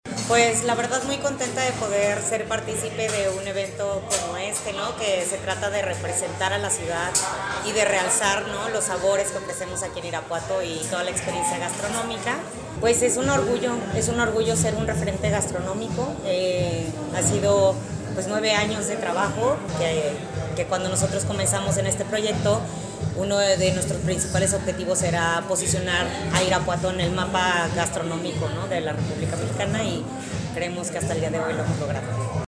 Gisela González Juérez, directotra de turismo municipal